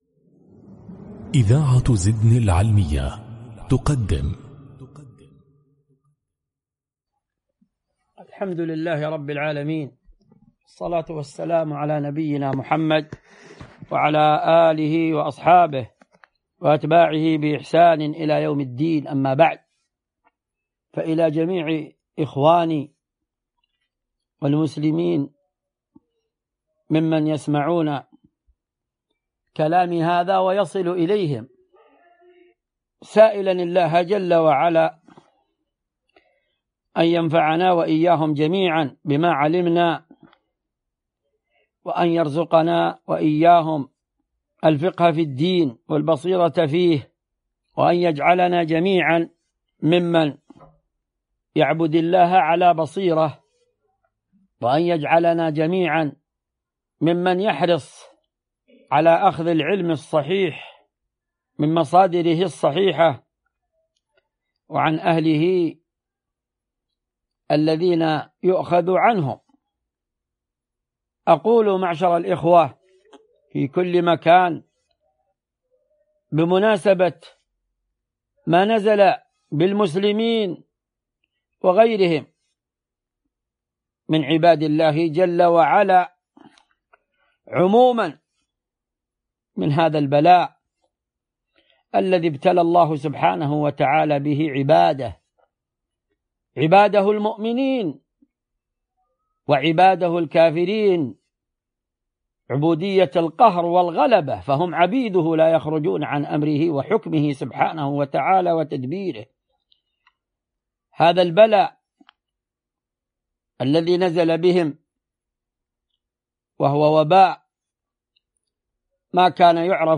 كلمة وعظية حول فيروس كورونا